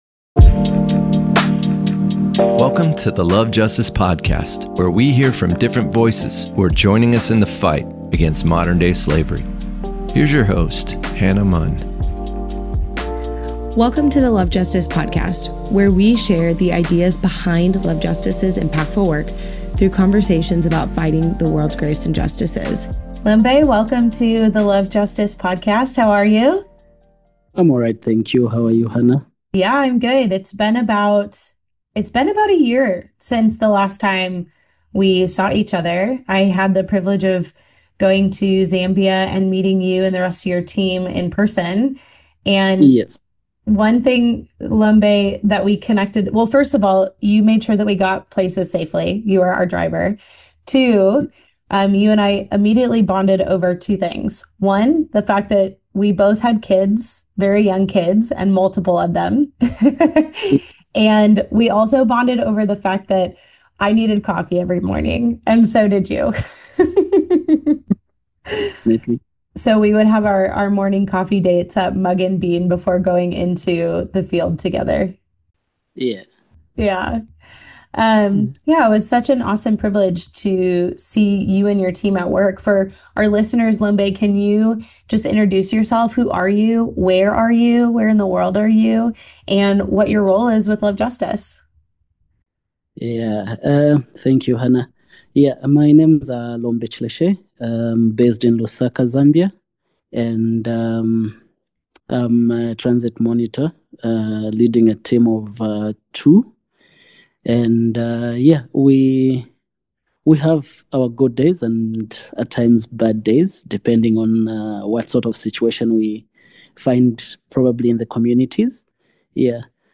Episode 29 of The LOVE JUSTICE Podcast: Monitor Interview